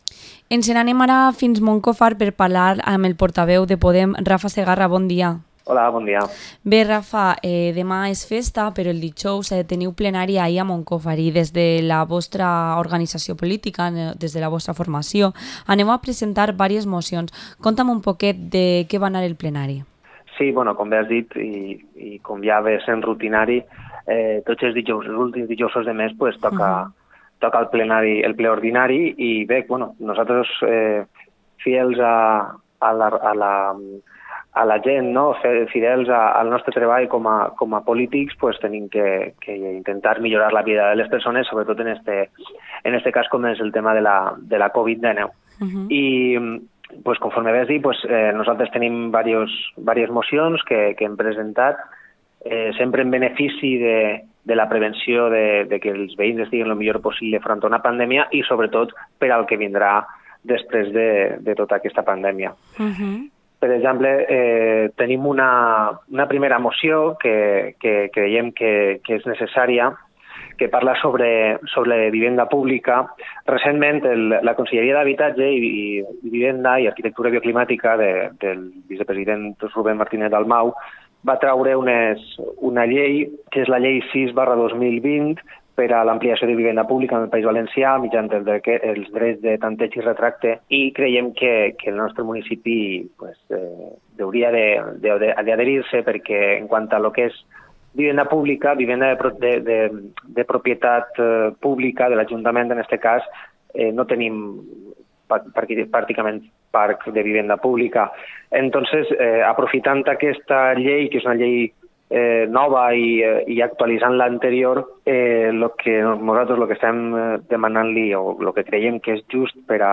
Entrevista al portavoz de Podem en el Ayuntamiento de Moncofa, Rafa Segarra